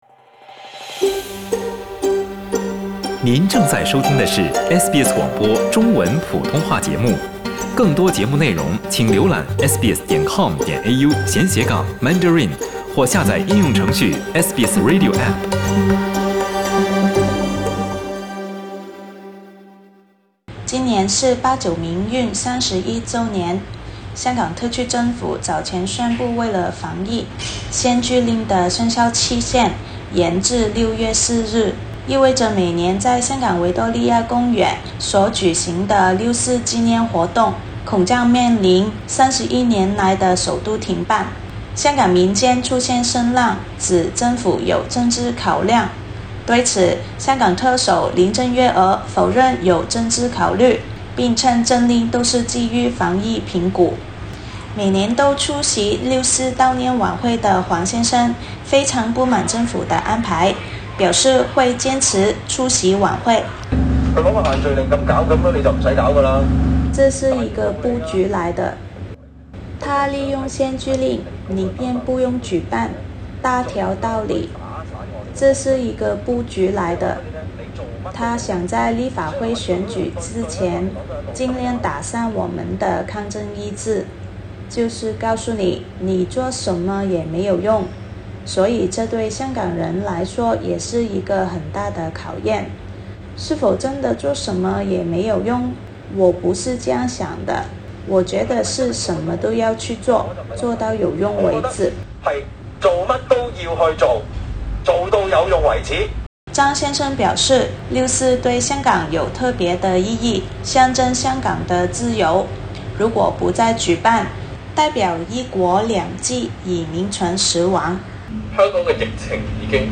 负责举办六四纪念活动的支联会表示，他们仍会坚持当晚出现在维园。点击上图收听报道。